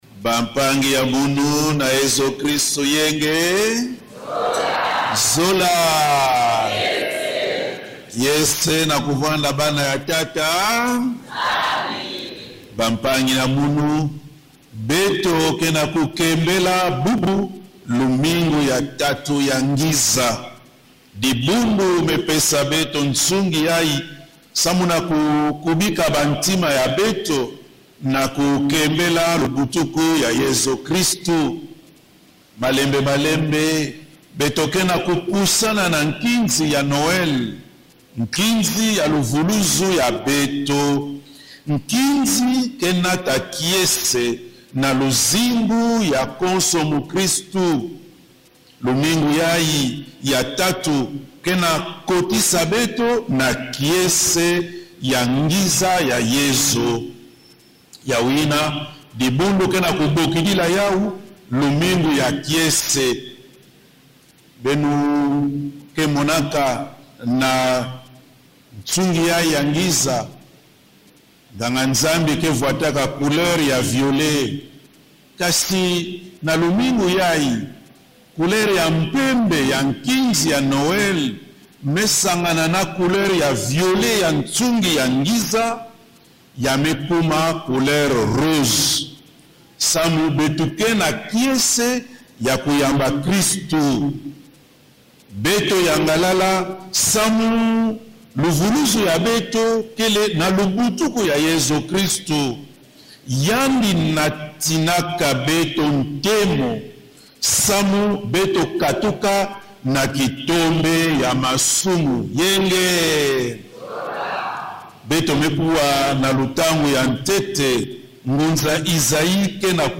La célébration eucharistique a été marquée par la confirmation de septante-six fidèles de la paroisse. Dans son homélie, inspirée des textes liturgiques du jour, l’Évêque a invité les chrétiens à cultiver trois attitudes fondamentales dans l’attente de la venue du Christ : la joie, à laquelle appelle la liturgie de ce troisième dimanche de l’Avent ; l’espérance, dans un contexte national marqué par de nombreuses souffrances ; et la conversion, condition nécessaire pour accueillir la lumière de l’Emmanuel.